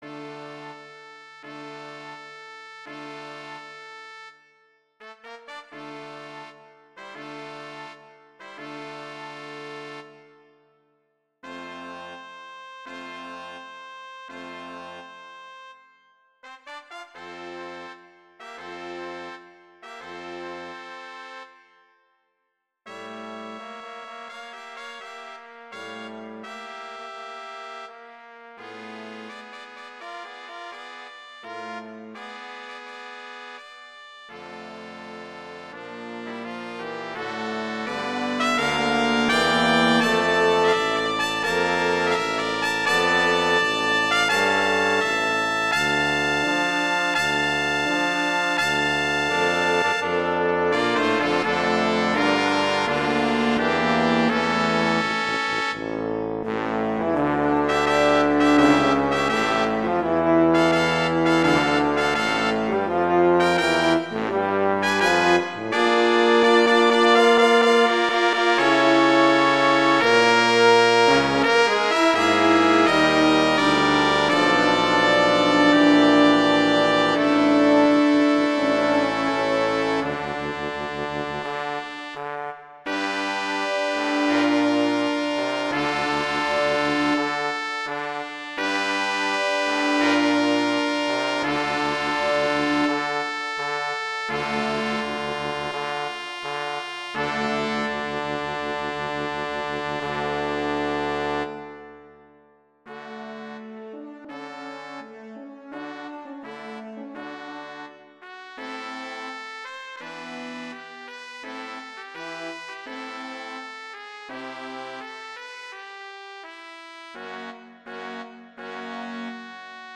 classical, halloween, holiday
D minor
♩=42 BPM
trumpet I in Eb:
trumpet II in Bb:
horn in F:
euphonium or trombone:
tuba: